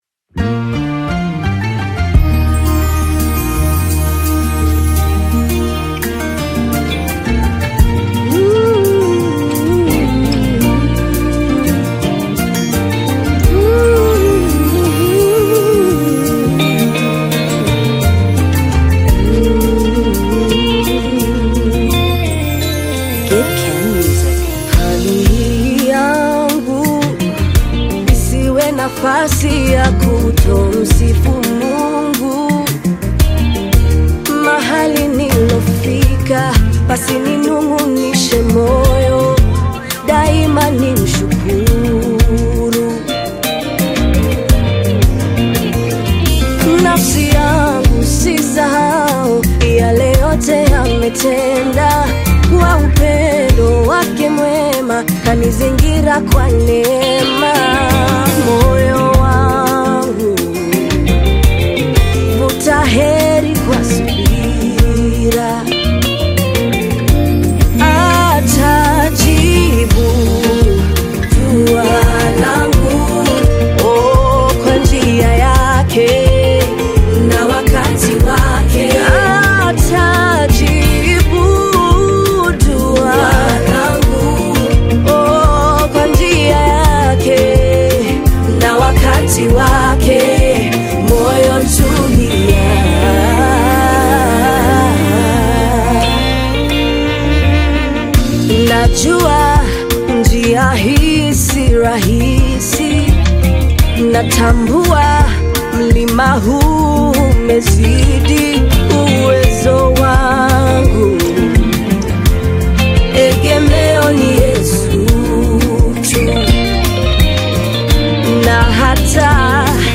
gospel song
African Music